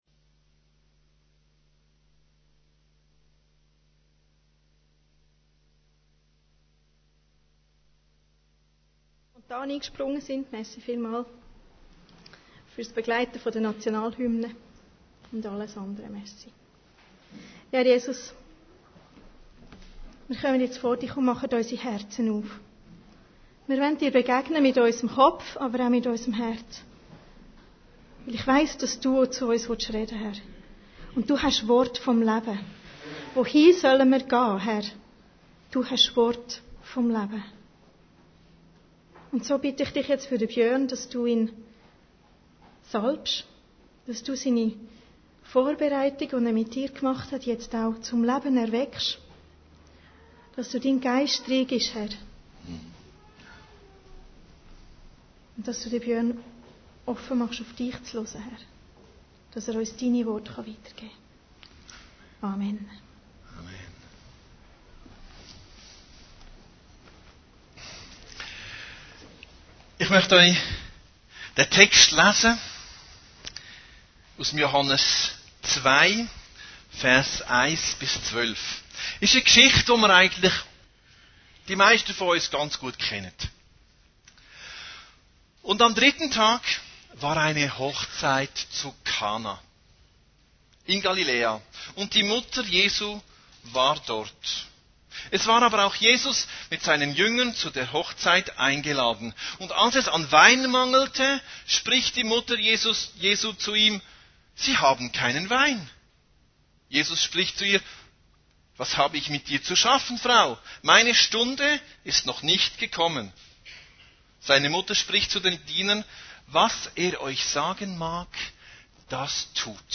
Predigten Heilsarmee Aargau Süd – Jesus an einem Hochzeit